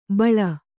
♪ baḷa